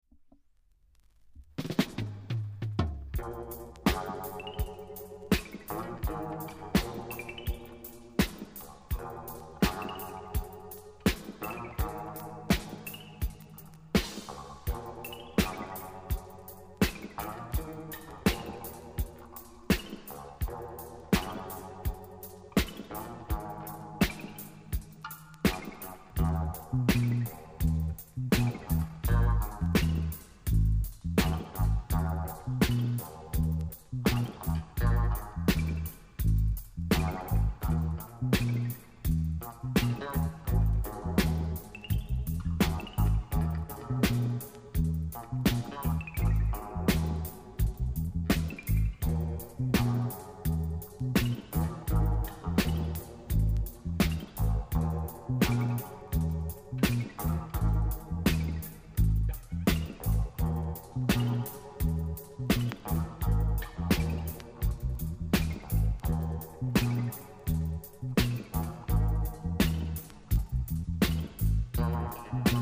サイドB DUB